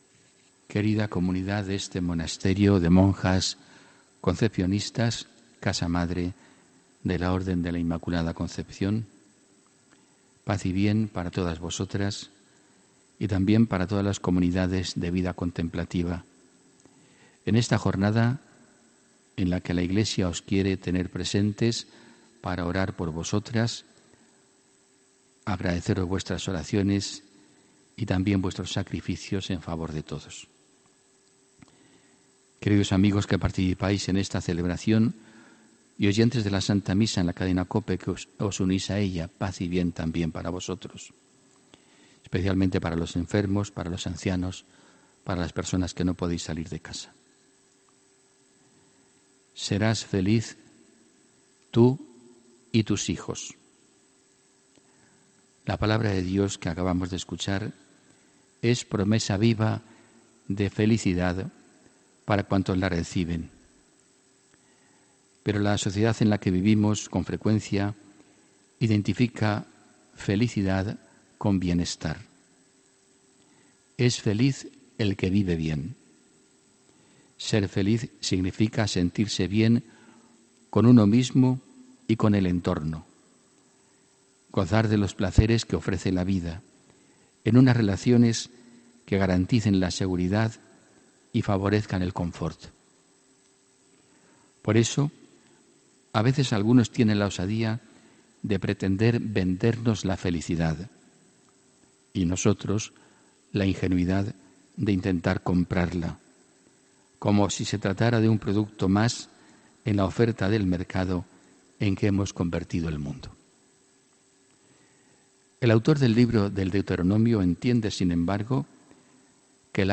HOMILÍA 27 MAYO 2018